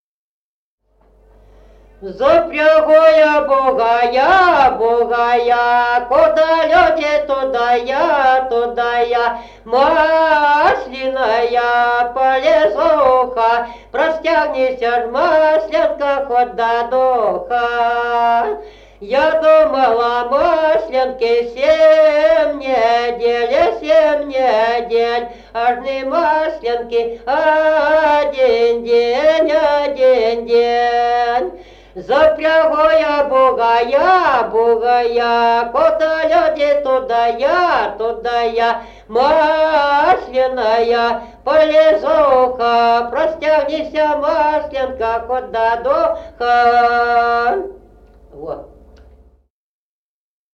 Народные песни Стародубского района «Запрягу я бугая», масленичная.